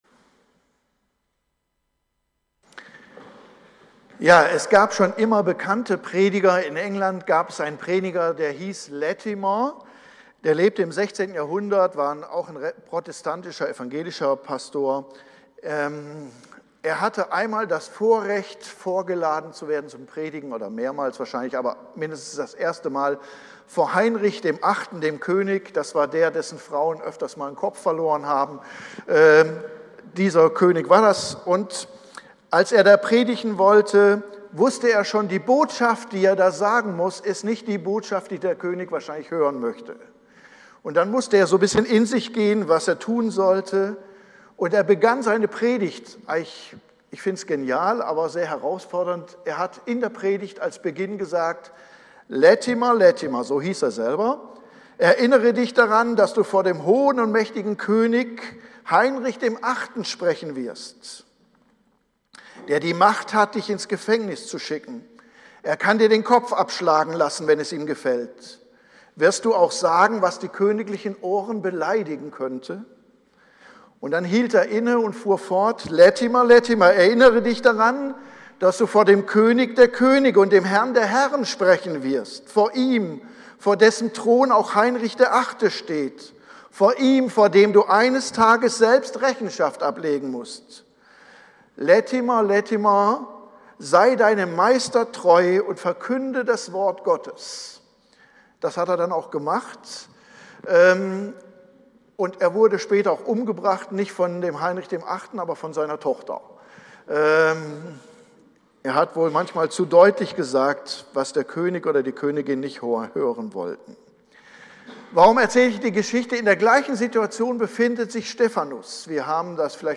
Predigt-am-16.02-online-audio-converter.com_.mp3